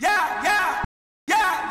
chant (la flame).wav